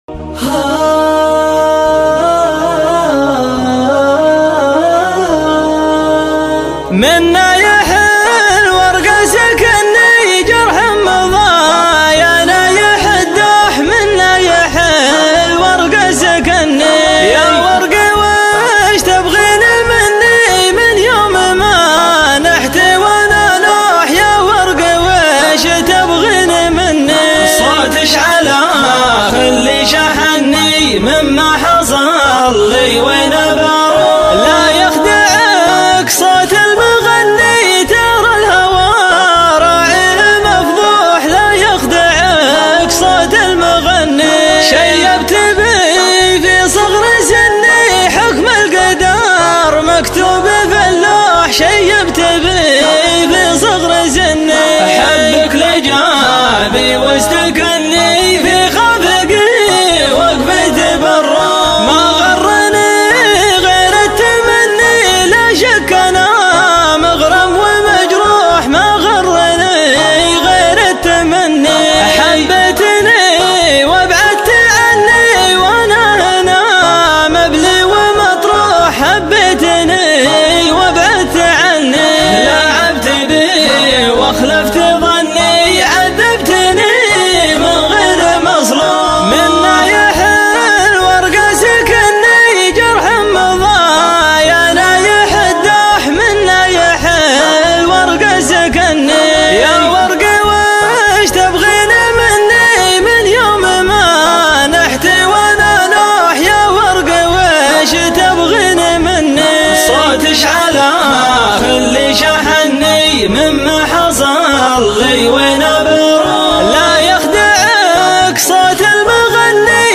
شيلة &quot